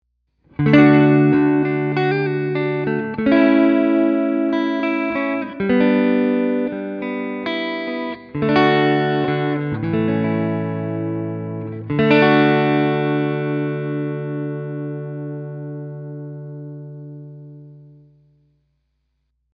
Tutte le clip audio sono state registrate con testata a valvole artigianale da 15W ispirata al Cornell Romany e cassa 1×12 equipaggiata con altoparlante Celestion A-Type impostato su un suono estremamente clean.
Suono pulito, senza effetti, solo chitarra e amplificatore
Chitarra: Fender Stratocaster (pickup al manico)
Clean.mp3